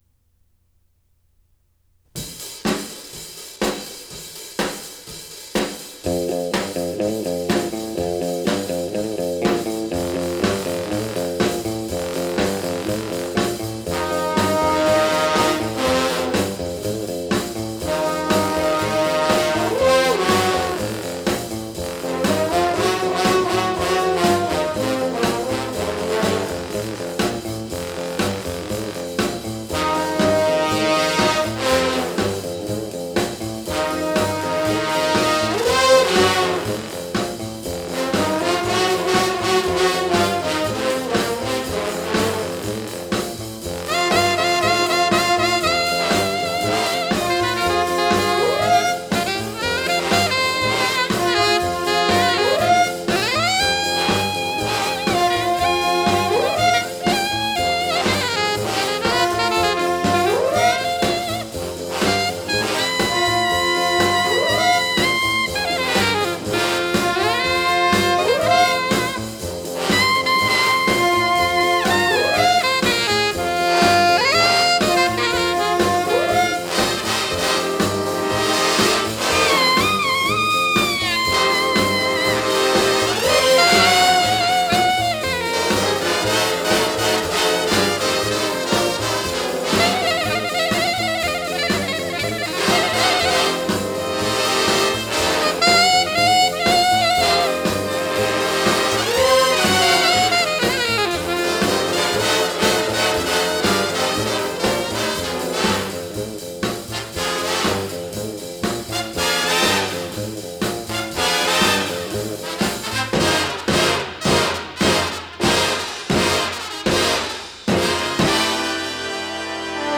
alto saxophonist
late-’50s jazz